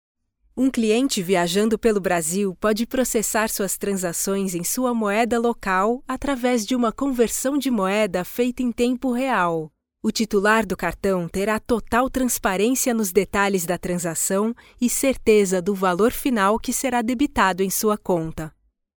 locutora brasil, brazilian voice over